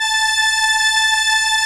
Index of /90_sSampleCDs/Roland LCDP09 Keys of the 60s and 70s 1/STR_ARP Strings/STR_ARP Solina